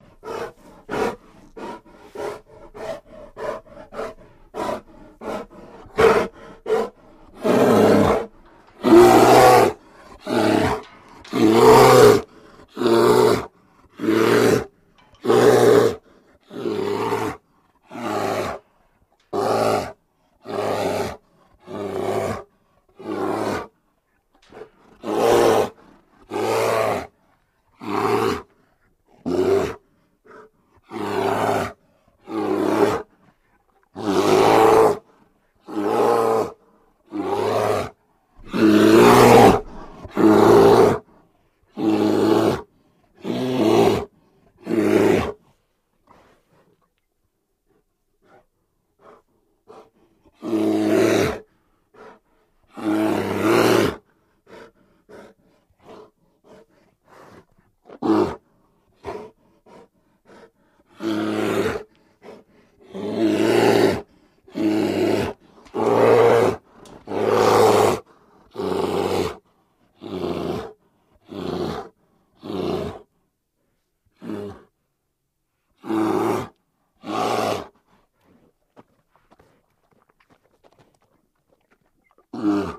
Kodiak Bear Roars And Breaths